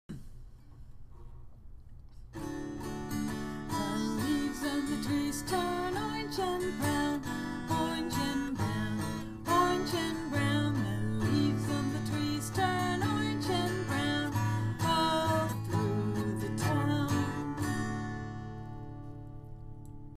Tune: The Wheels on the Bus"